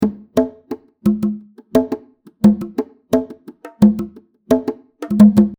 そこでループ素材のリバーブを除去して使い勝手の良い状態にすることはできないか試してみました。 Reverb Remover off Reverb Remover on ノブを回すだけで扱いやすい素材になりました。
Reverb-Remover-on.mp3